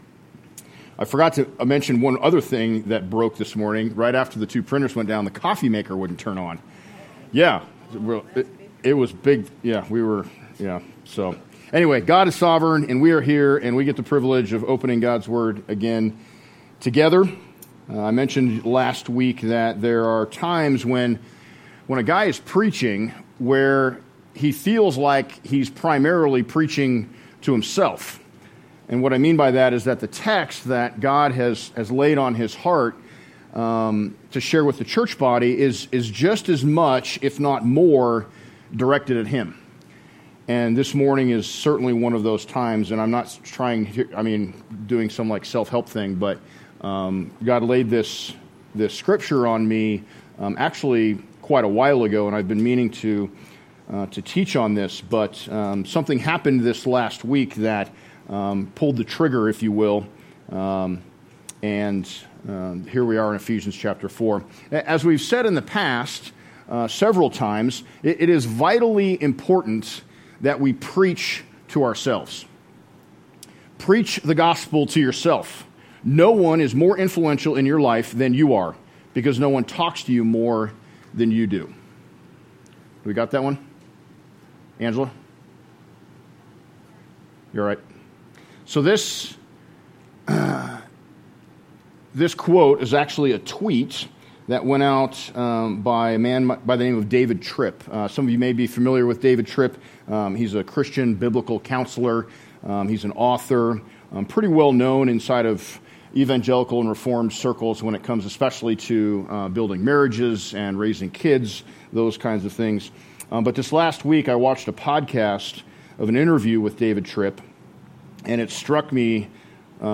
Message
7/15/18 Location: High Plains Harvest Church Passage